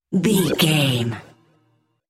Sound Effects
magical
mystical
special sound effects